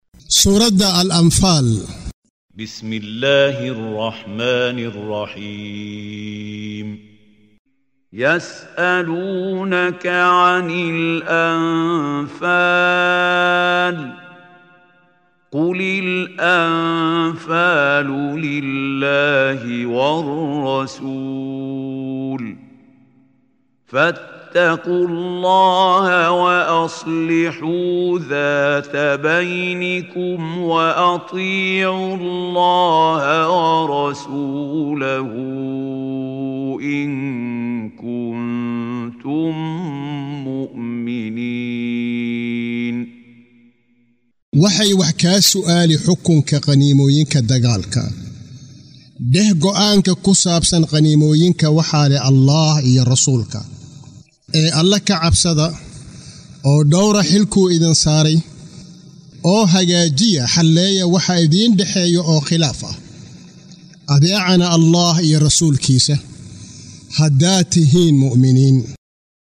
Waa Akhrin Codeed Af Soomaali ah ee Macaanida Suuradda Al-Anfaal ( Qaniimooyinka ) oo u kala Qaybsan Aayado ahaan ayna la Socoto Akhrinta Qaariga Sheekh Maxmuud Khaliil Al-Xusari.